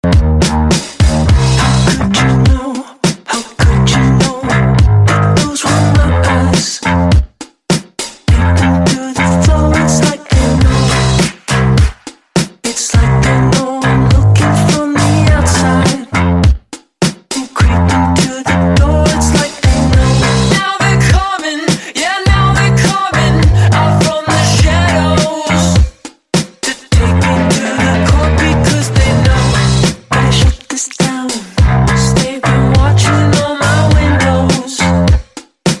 • Качество: 128, Stereo
мужской вокал
Electronic
спокойные
alternative
indie rock
бас-гитара